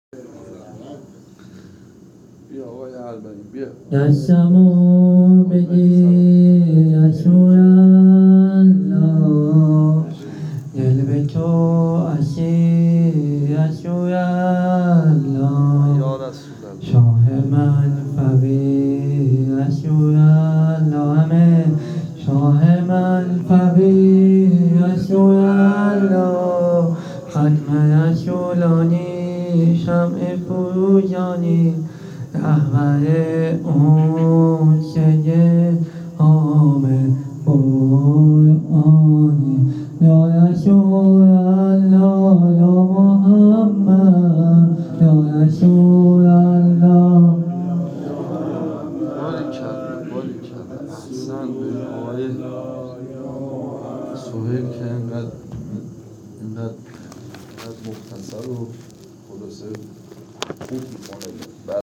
هیت هفتگی عشاق العباس تهران